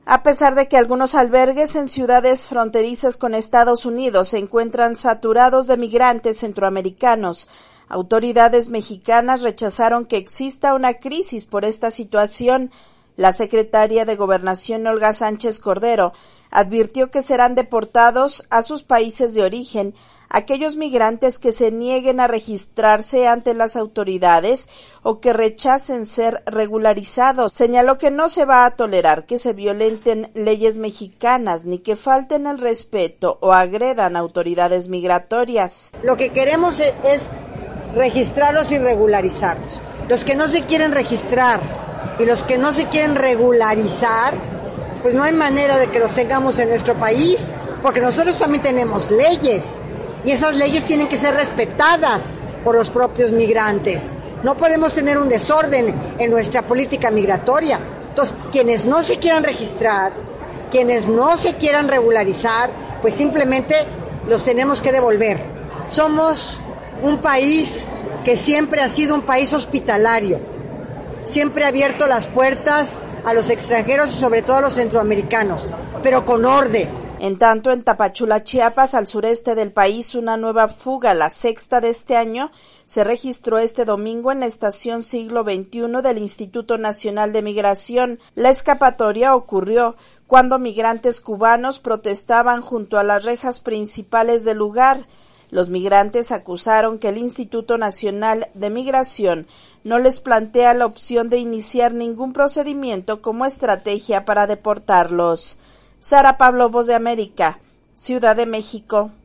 VOA: Informe desde México